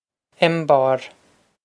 Embarr Em-bahr
This is an approximate phonetic pronunciation of the phrase.